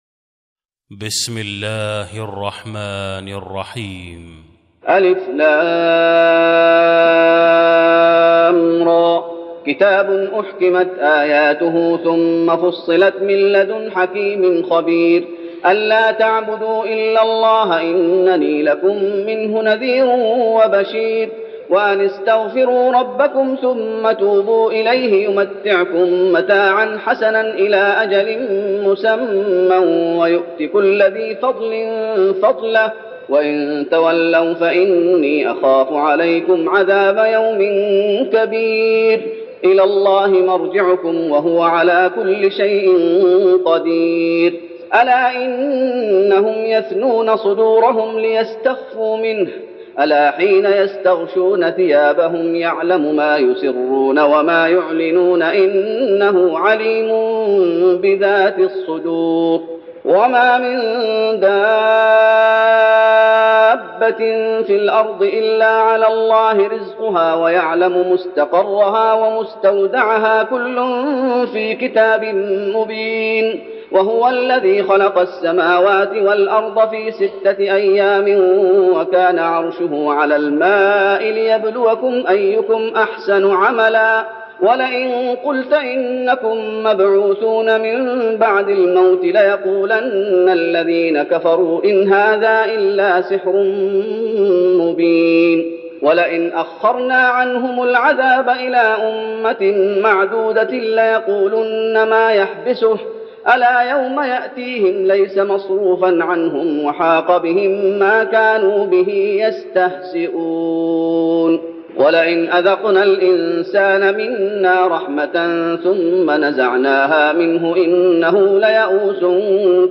تراويح رمضان 1412هـ من سورة هود (1-49) Taraweeh Ramadan 1412H from Surah Hud > تراويح الشيخ محمد أيوب بالنبوي 1412 🕌 > التراويح - تلاوات الحرمين